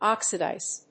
音節ox･i･dise発音記号・読み方ɑ́ksədàɪz|ɔ́k-